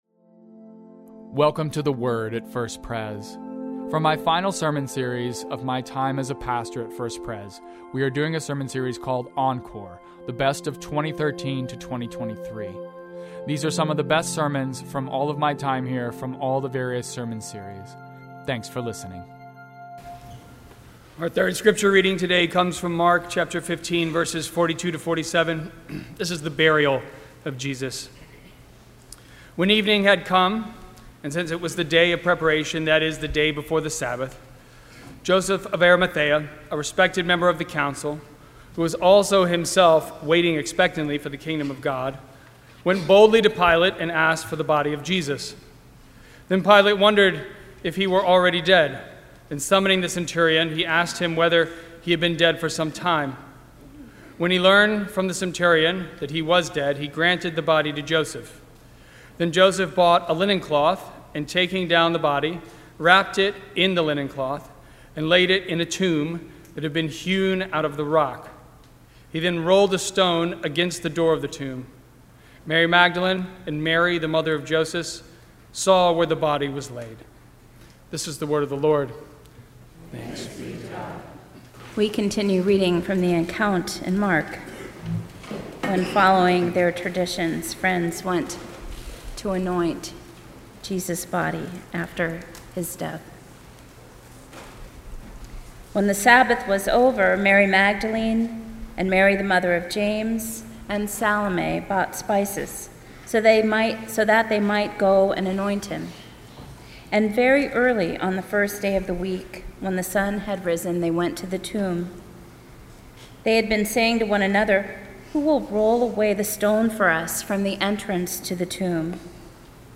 Sermons
2023 This Sunday we have a special performance of Mozart’s Requiem in service.